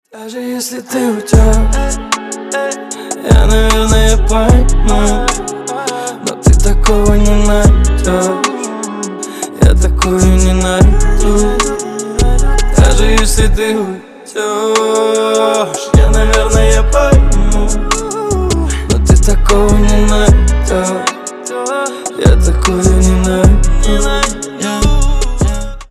• Качество: 320, Stereo
красивый мужской голос
спокойные
басы